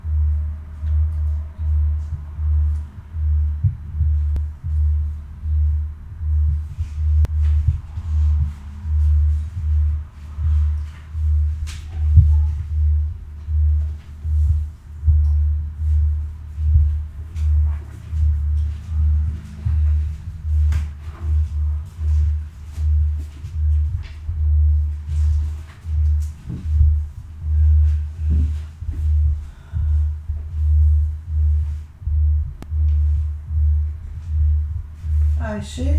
"Musik" aus den Wänden
Ich war zu diesem Zeitpunkt zu Hause, im Hintergrund sind daher auch meine Gehgeräusche zu hören, diese merkwürdig pulsierenden Geräusche kommen aber durch die Musikanlage von oben.